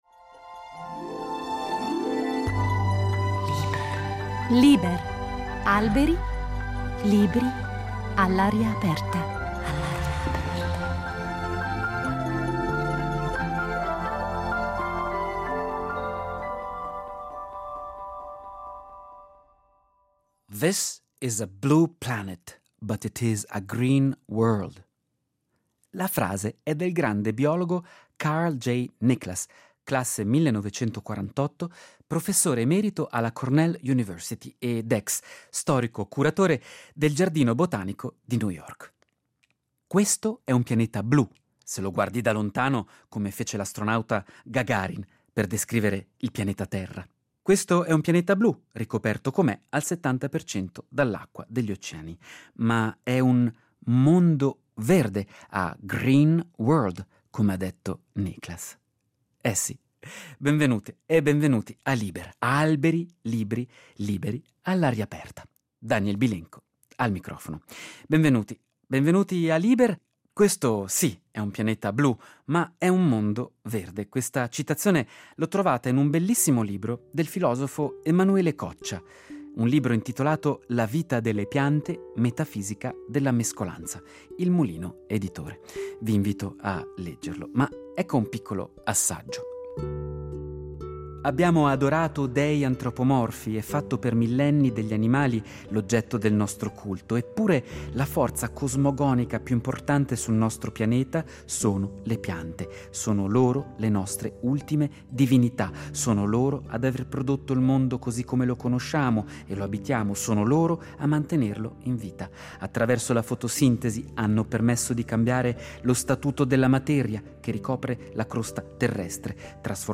all’aria aperta